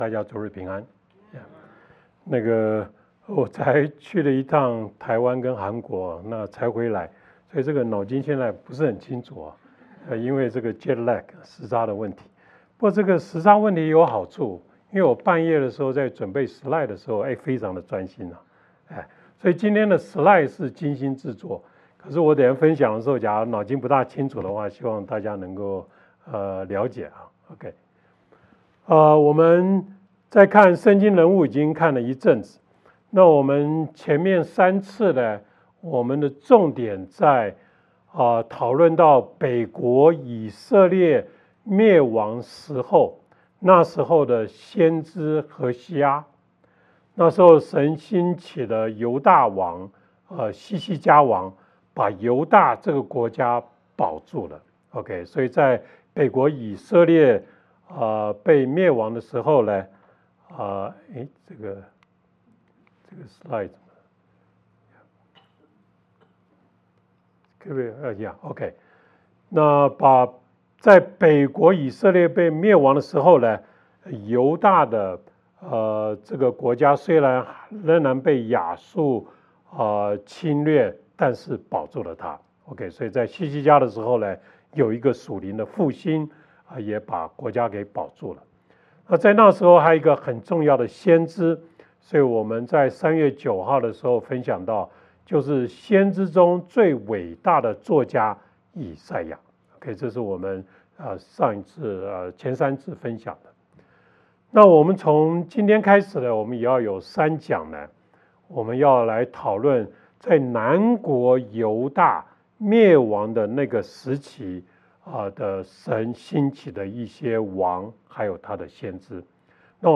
主頁 Home 關於我們 About Us 小組 Small Groups 事工 Ministry 活動 Events 主日信息 Sermons 奉獻 Give 資源 Resources 聯絡我們 Contact 南國猶大滅亡前的約西亞王